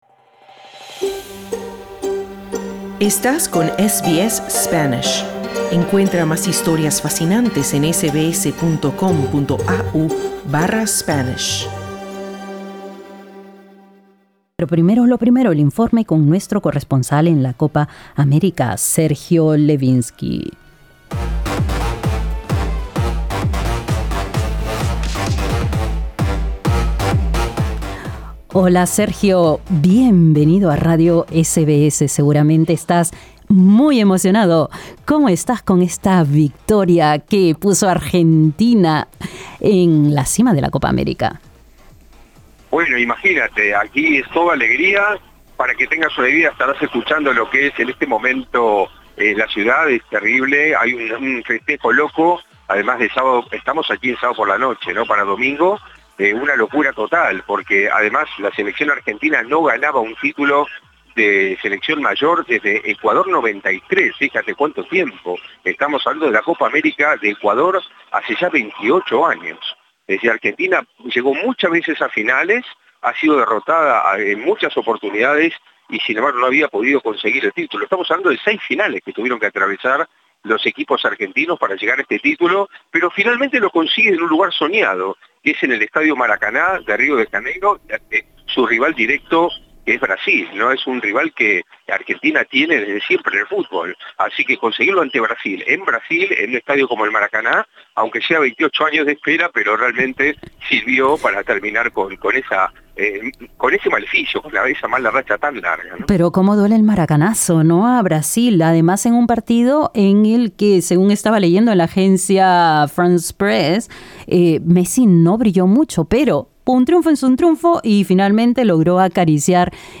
Con el informe nuestro corresponsal